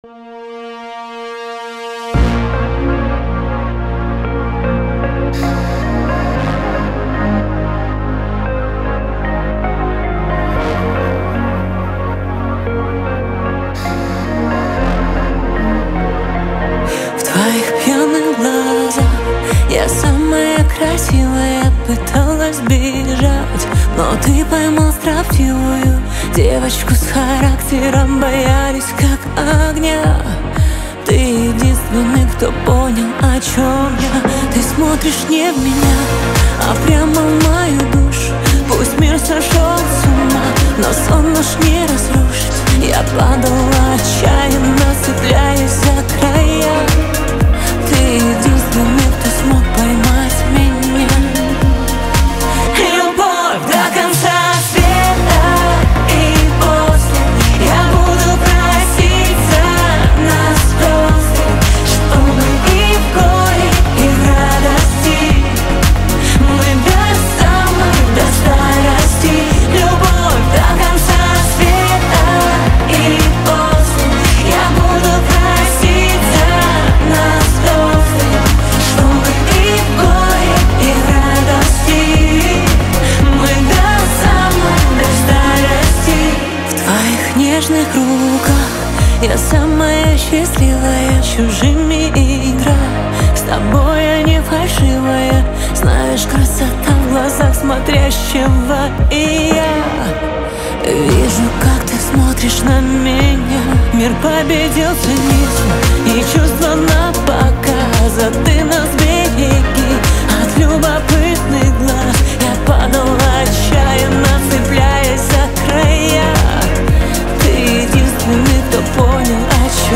Жанр: Украинская